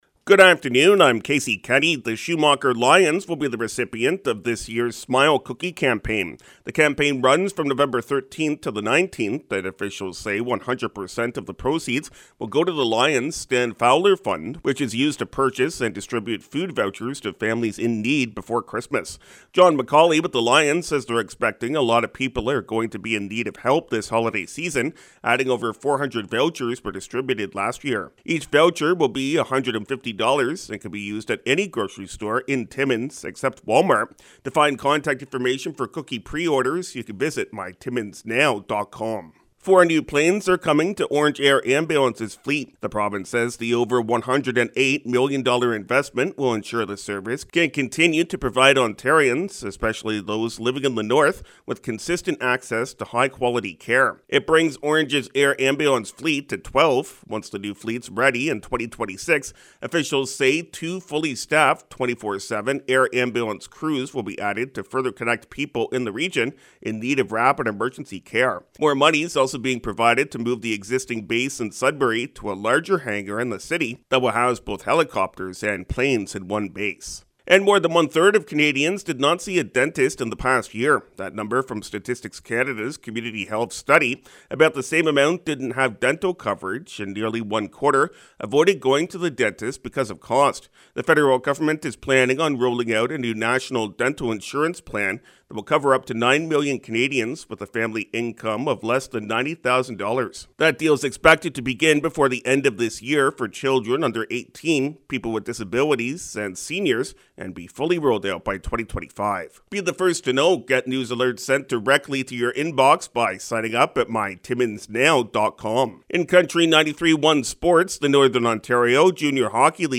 5:00pm Country 93.1 News – Tue., Nov. 07, 2023